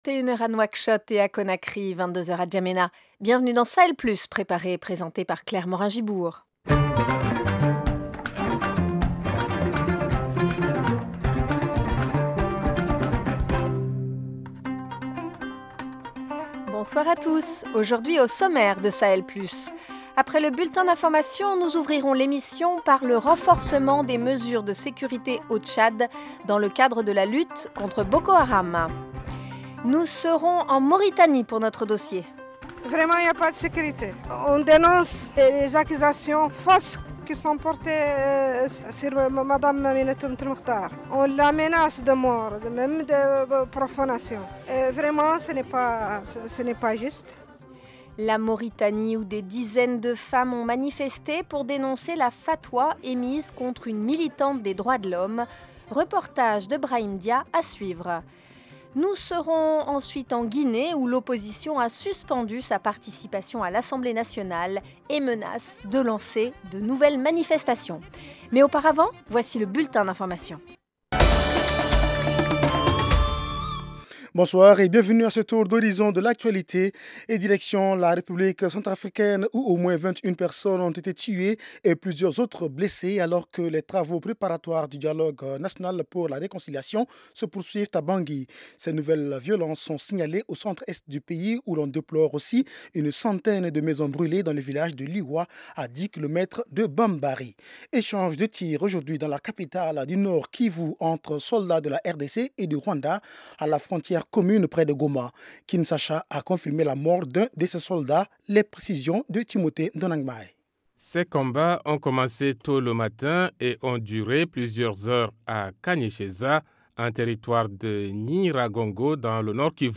Au programme : renforcement des mesures de sécurité au Tchad pour lutter notamment contre Boko Haram. Dossier : en Mauritanie, des dizaines de femmes ont manifesté pour dénoncer la fatwa émise contre une militante des droits de l’homme. Reportage